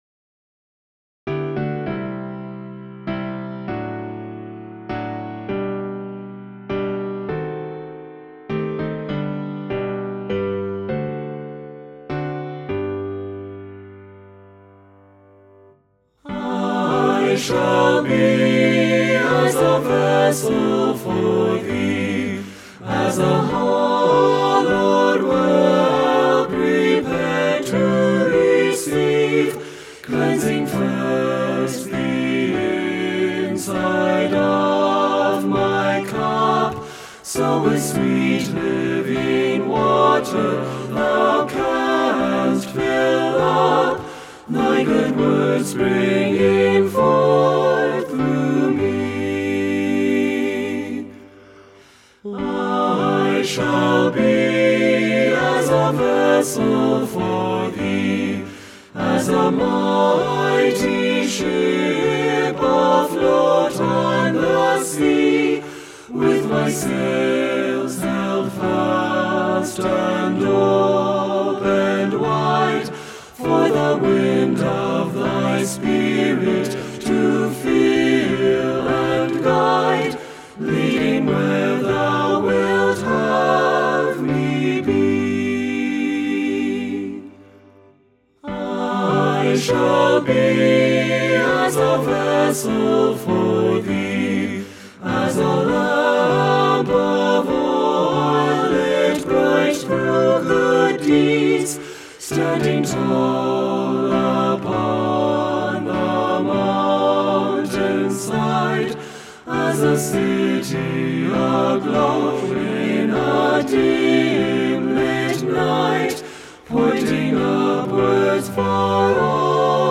SATB Hymn
SATB Traditional Hymn